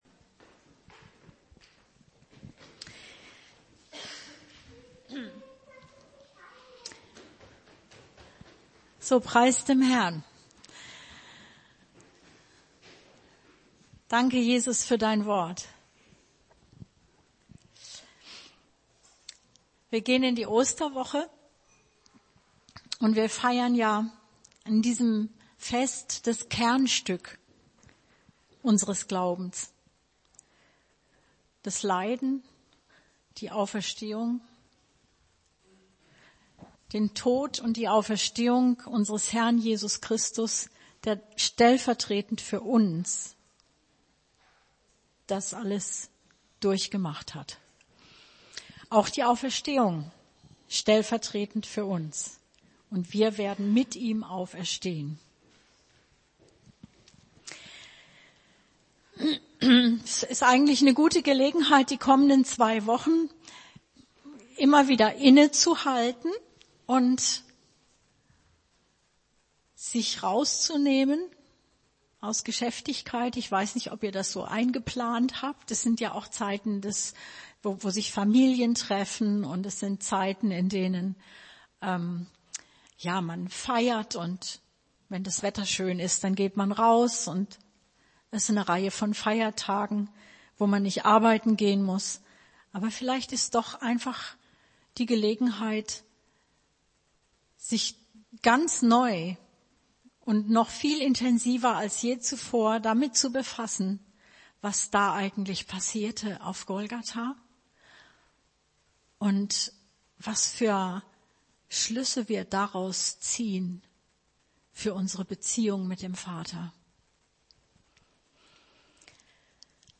Predigt 25.03.2018: Schau auf den Sohn!